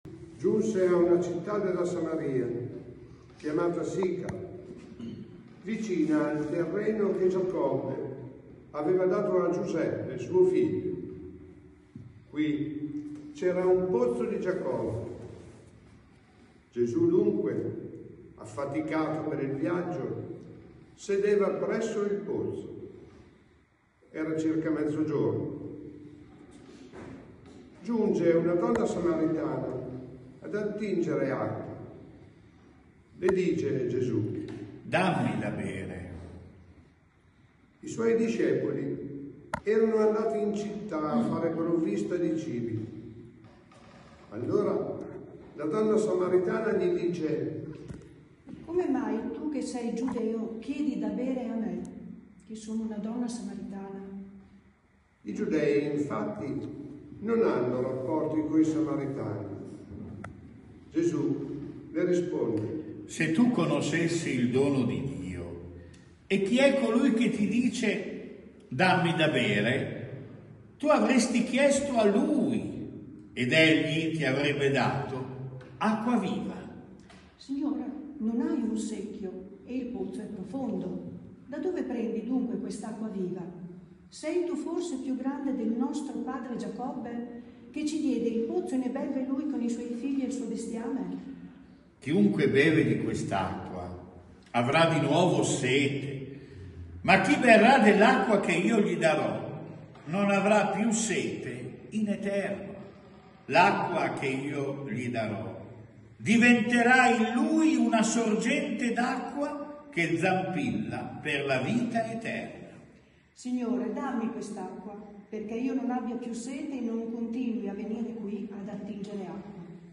Omelia III quar. Anno A – Parrocchia San Pellegrino